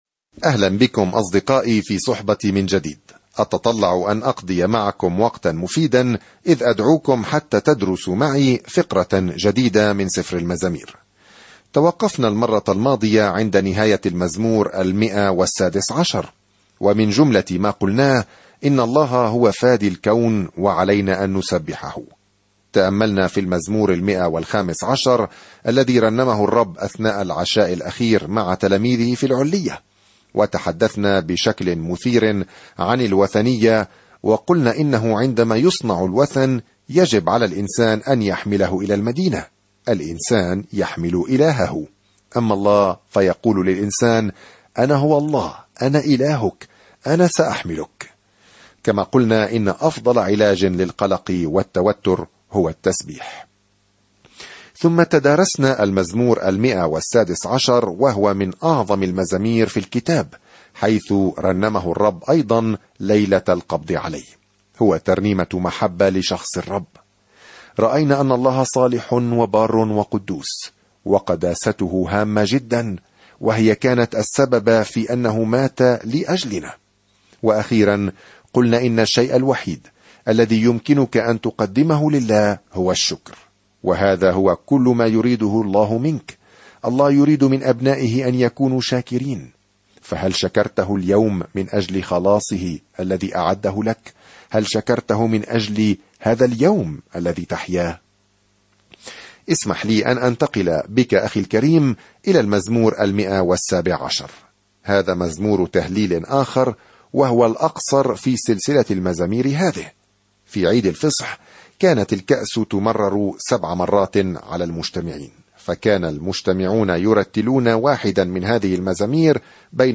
الكلمة اَلْمَزَامِيرُ 117 اَلْمَزَامِيرُ 118 يوم 45 ابدأ هذه الخطة يوم 47 عن هذه الخطة تعطينا المزامير أفكارًا ومشاعر مجموعة من التجارب مع الله؛ من المحتمل أن كل واحدة تم ضبطها في الأصل على الموسيقى. سافر يوميًا عبر المزامير وأنت تستمع إلى الدراسة الصوتية وتقرأ آيات مختارة من كلمة الله.